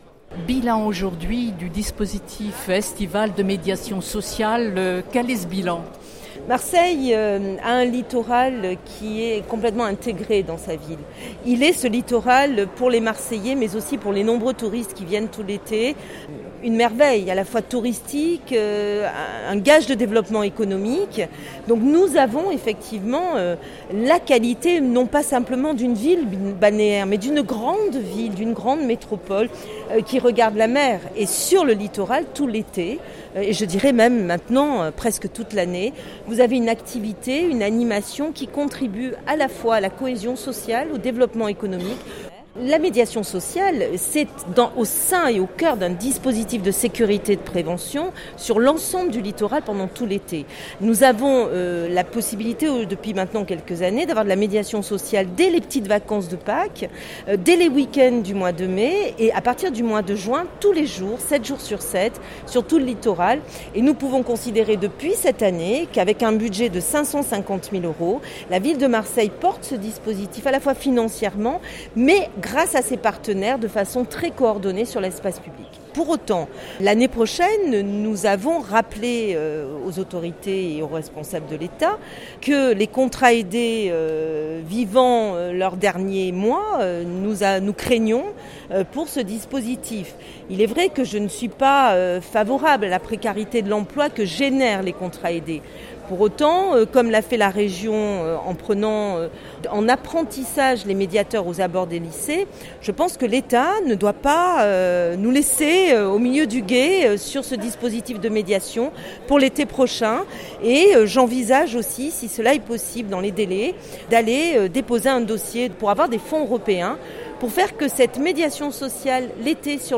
Présents sur la Calanque de Sormiou, l’île du Frioul, la Corniche, le Vallon des Auffes, le Prado Sud et la Pointe-Rouge, le port de Montredon, l’Estaque village et les plages de Corbières, les médiateurs ont été chargés d’établir le lien avec les usagers et de prévenir les actes d’incivisme et les comportements à risque. son_copie_petit-170.jpg«La mairie de Marseille prend seule en charge le coût total du dispositif 553 000 euros», précise Caroline Pozmentier, adjointe au maire de Marseille en charge de la Sécurité publique et de la Prévention de la délinquance.